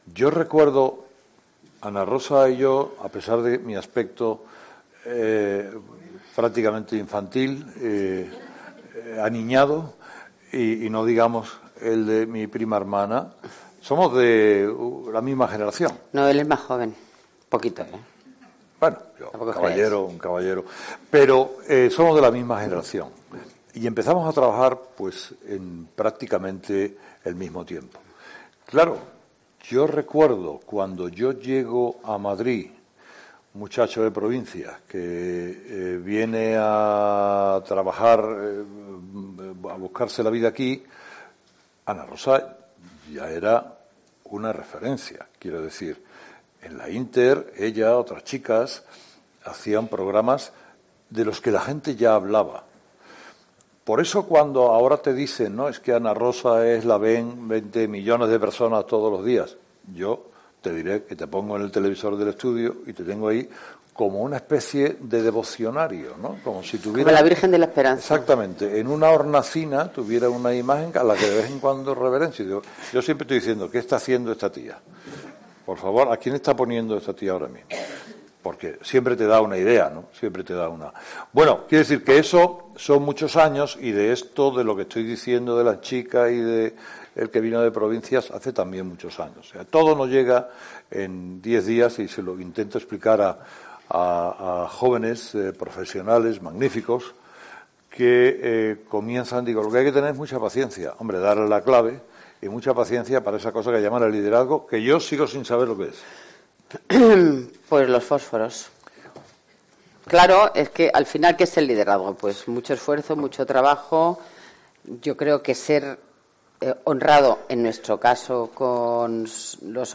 Carlos Herrera ha participado este martes como ponente en la I Jornada Mujeres y Liderazgo